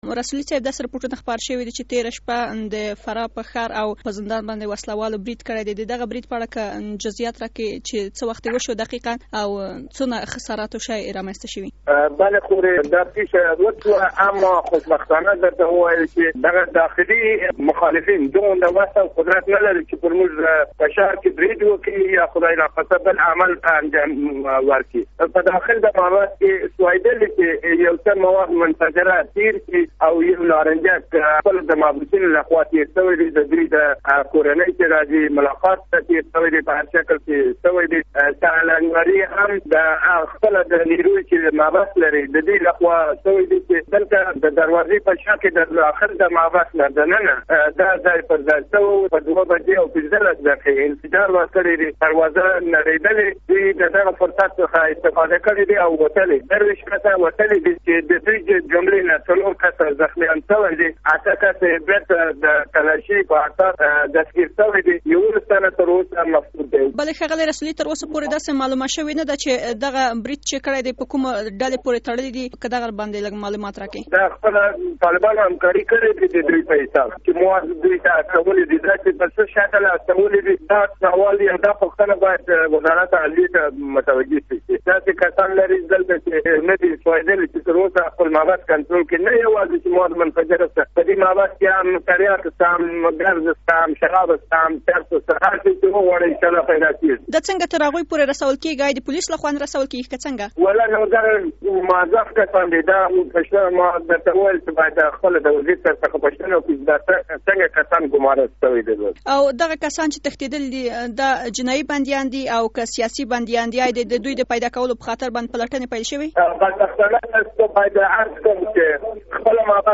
د فراه ولايت له مرستيال او سرپرست محمد يونس رسولي سره مركه